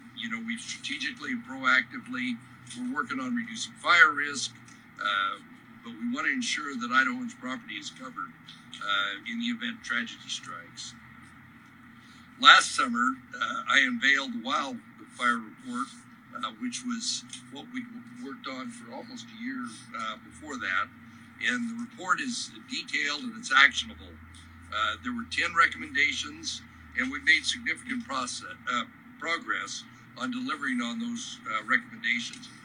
BOISE, ID – Idaho Governor Brad Little held a press conference today to update Idahoans on how agencies are working to ensure the state does not experience tragic wildfire events like the ones raging in southern California this month.